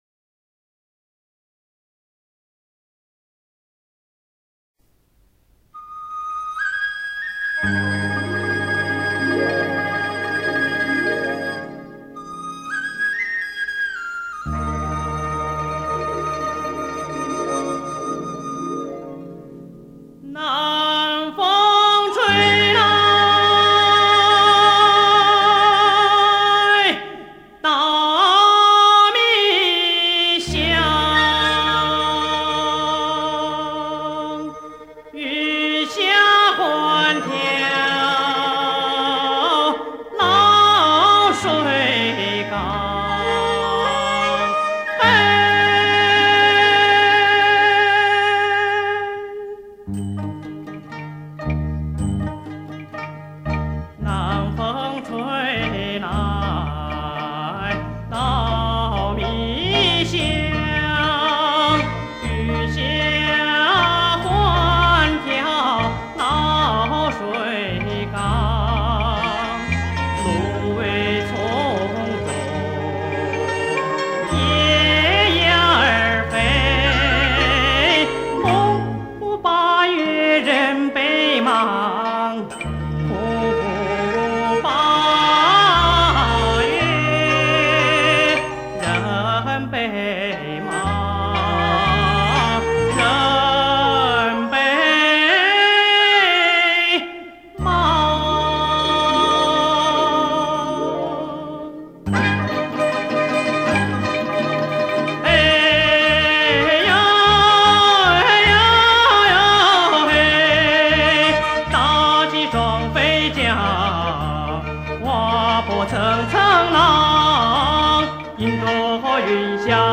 引子部分笛子引出高亢明豁的歌声，似是烟波浩淼的水面上有值小舟缓缓驶来。进入讲述段落的部分，曲调带有地域方言的韵味。